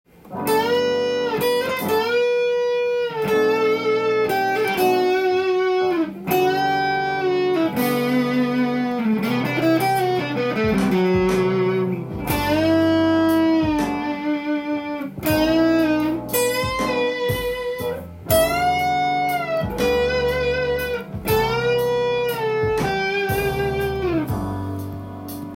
チョーキングと言えばエレキギターの特許ともいえる奏法です。
音源にあわせて譜面通り弾いてみました
3拍子になっています。
リズムがゆっくりなので焦らずにチョーキングの音程に
keyがGになっているので